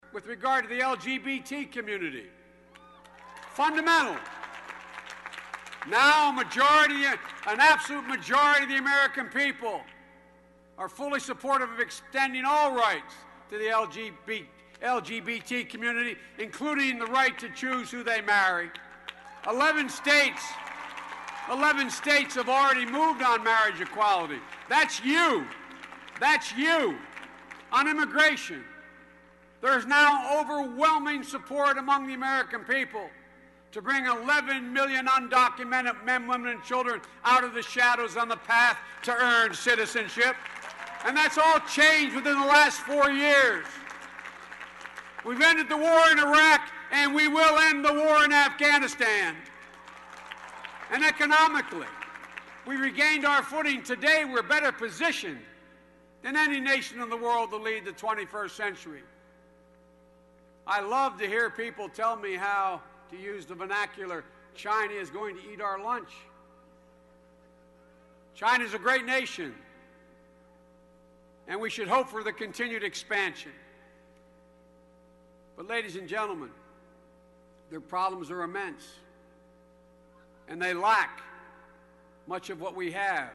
公众人物毕业演讲第410期:拜登2013宾夕法尼亚大学(8) 听力文件下载—在线英语听力室